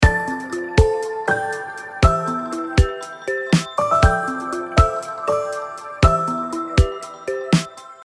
10秒BGM （13件）